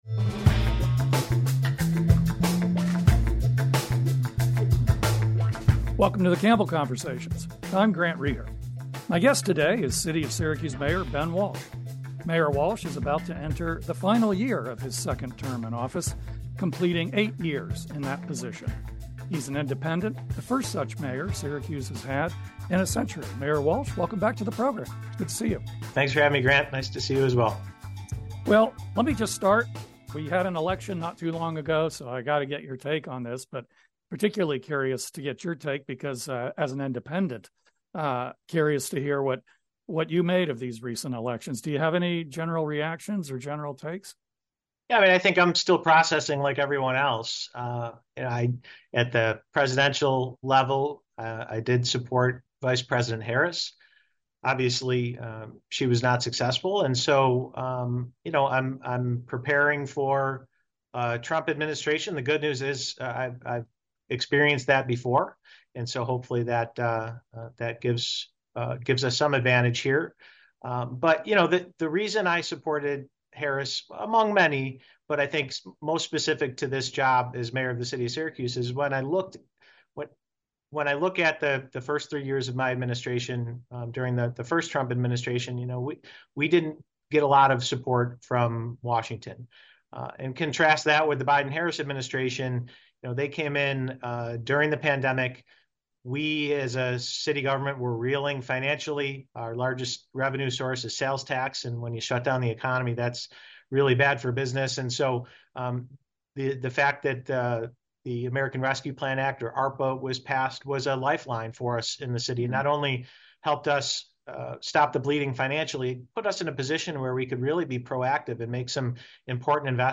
1 Debate